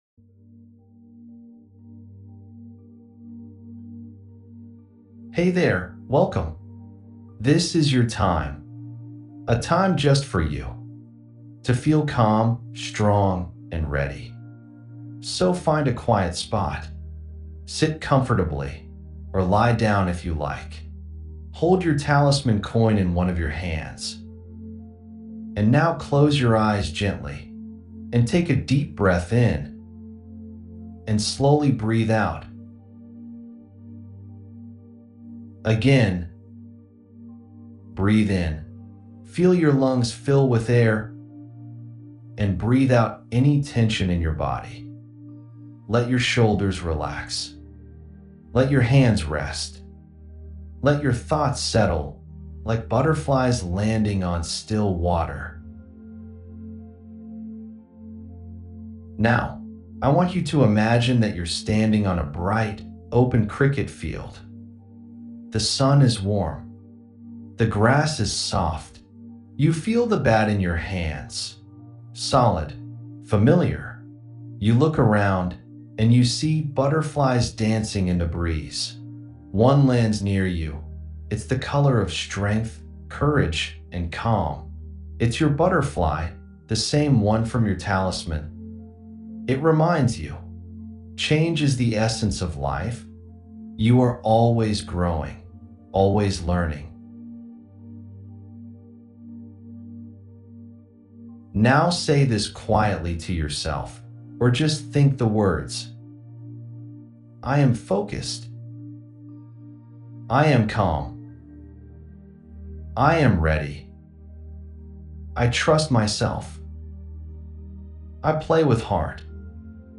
A downloadable 5-minute guided audio session to help your daughter prepare mentally for matches.
Girls_cricket_mindfulness_demo.mp3